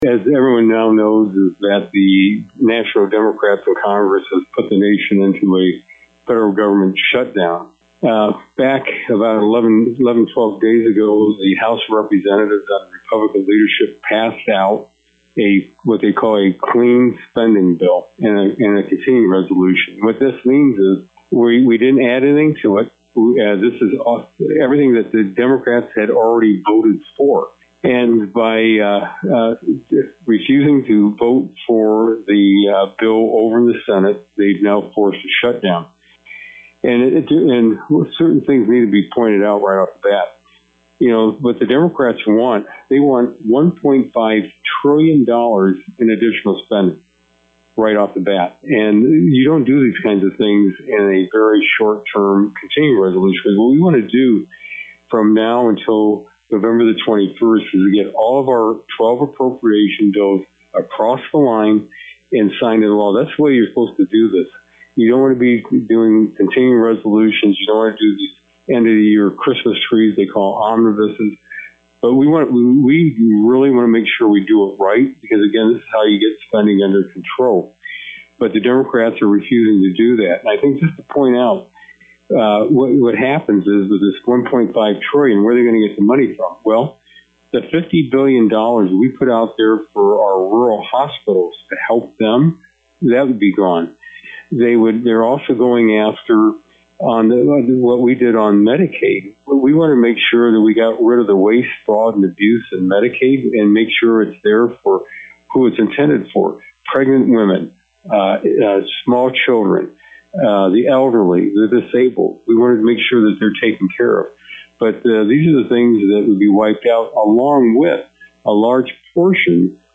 US Congressman Bob Latta-R-Bowling Green comments on the shut down of the US Government: